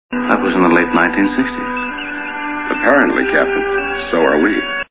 Captain Kirk - Mr. Spock